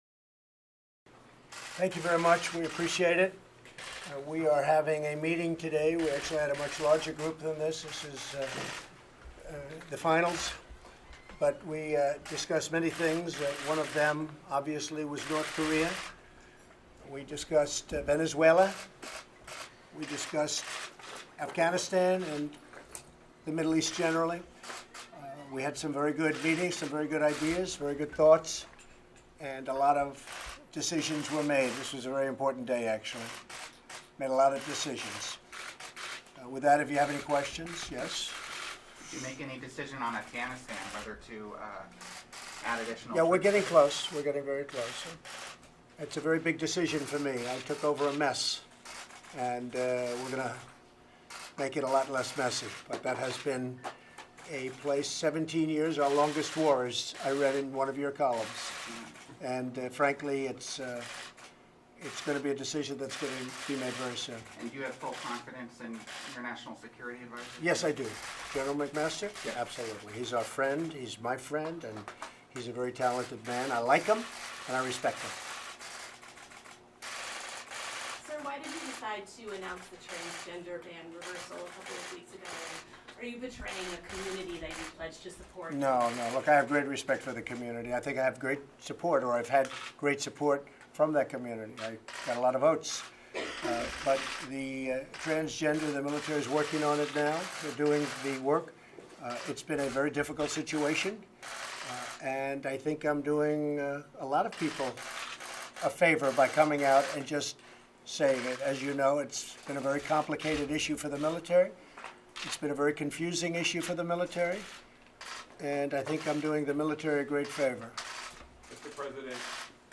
U.S. President Donald Trump speaks to the press following a meeting with his national security advisors